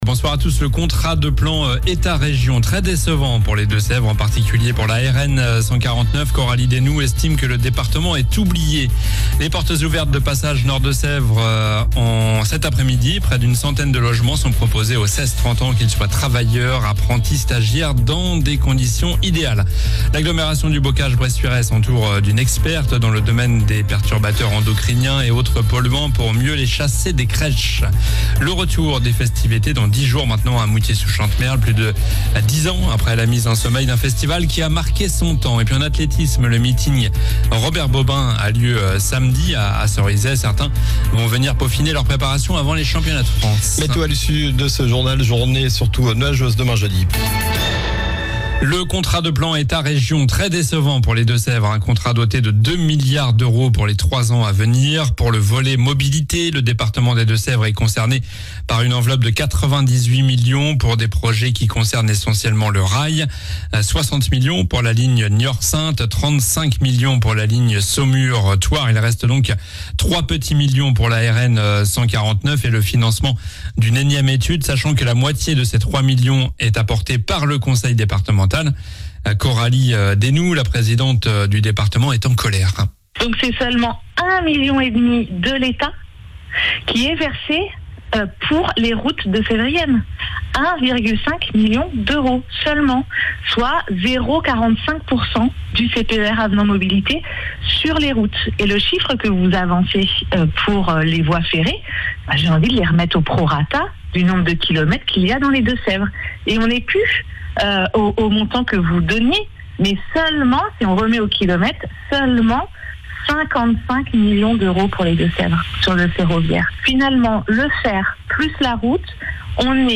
L'info près de chez vous